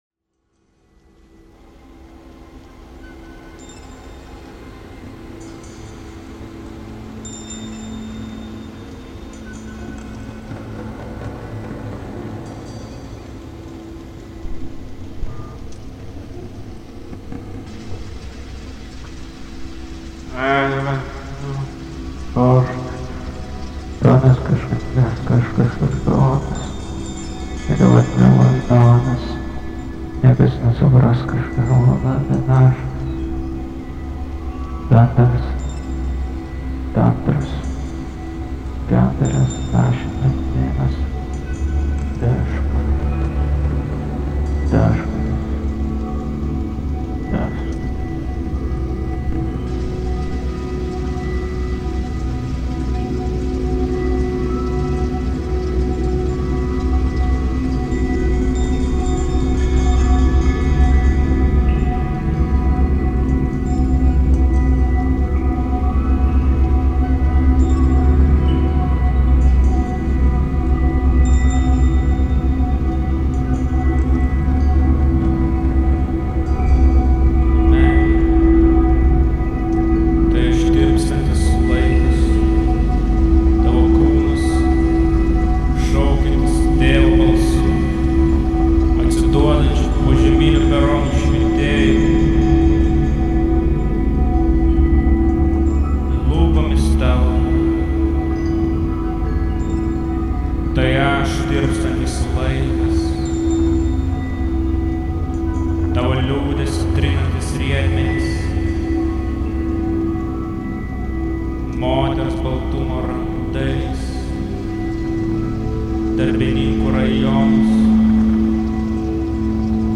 Vaizdas:Is koncerto VU bibliotekoje.mp3